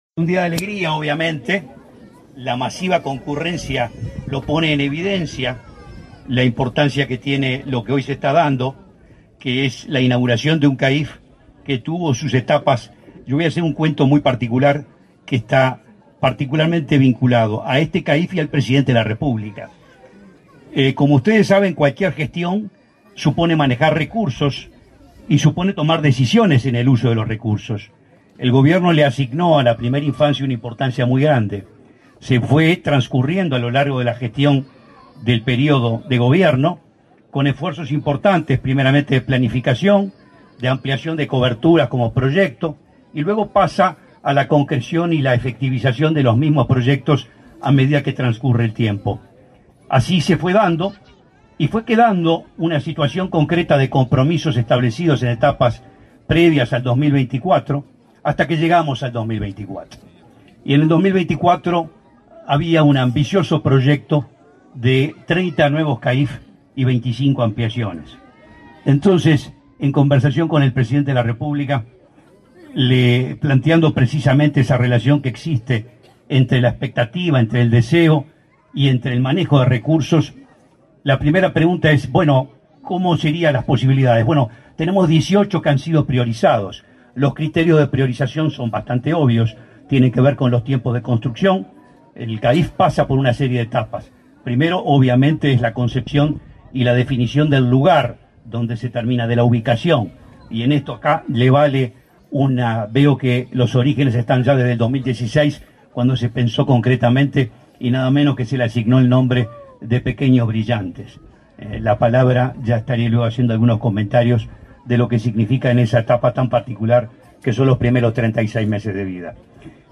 Palabras del presidente del INAU, Guillermo Fossati
Con la presencia del presidente de la República, Luis Lacalle Pou, se realizó la inauguración de un centro de atención a la infancia y la familia en
En el evento disertó el titular del INAU, Guillermo Fossati.